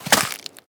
Sfx_creature_snowstalkerbaby_walk_08.ogg